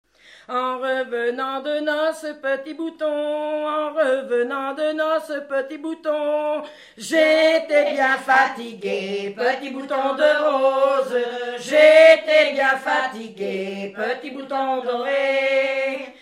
Enquête Arexcpo en Vendée
chansons traditionnelles islaises